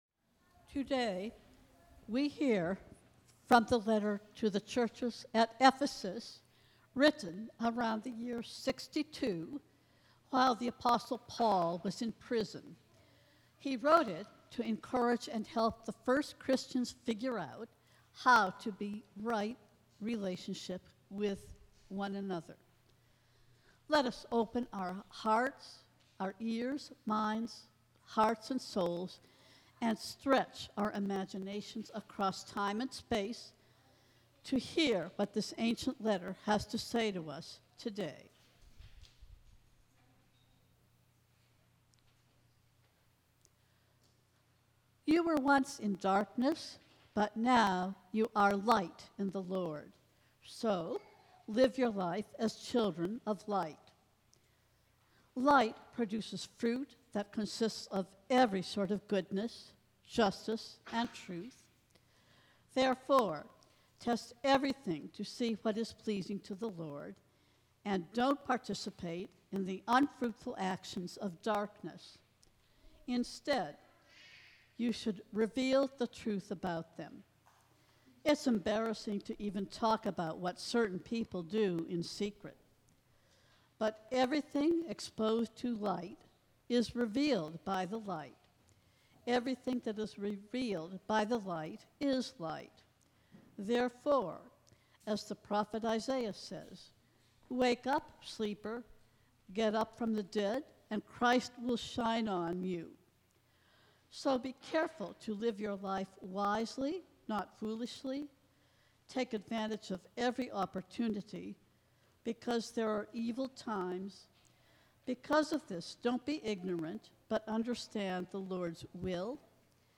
About Us Sermons Who is Our Neighbor?